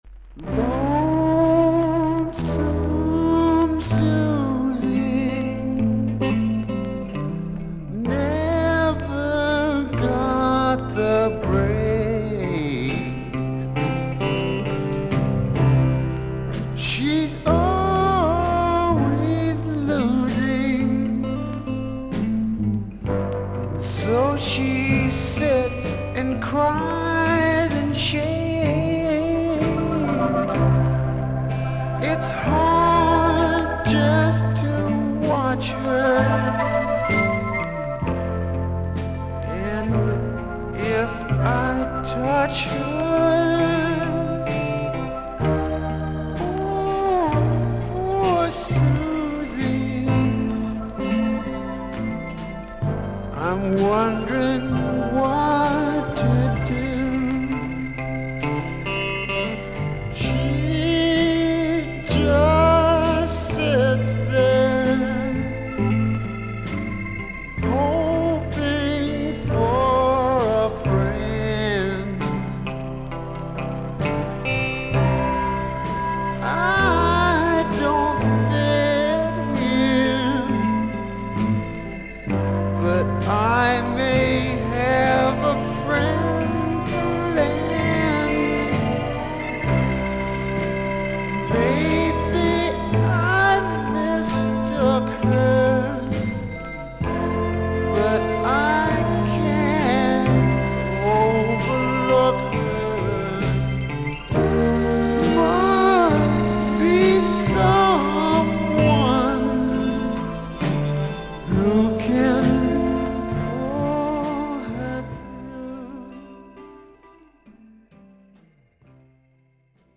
全体にはそれほどは目立たないのですが、静かなB4ではノイズがあります。。
少々軽いパチノイズの箇所あり。少々サーフィス・ノイズあり。クリアな音です。